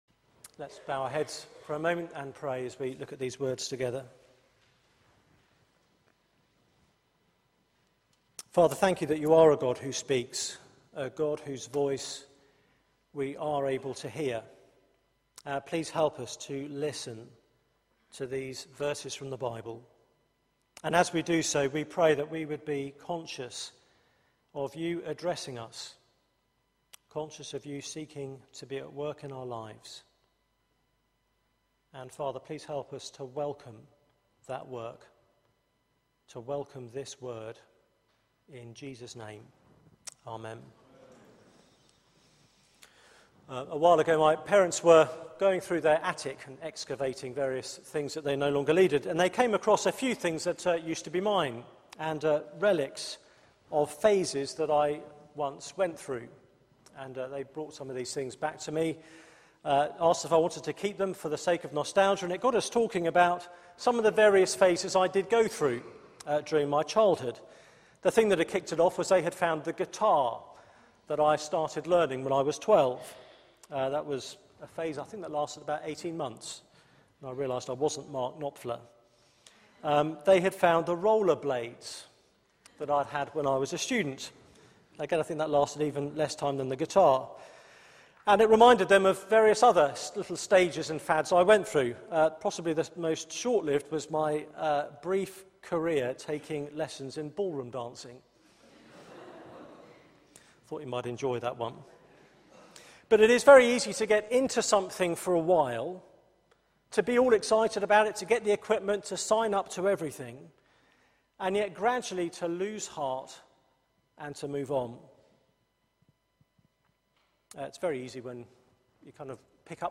Media for 6:30pm Service on Sun 29th Sep 2013 18:30 Speaker
Theme: Hearing God's voice Sermon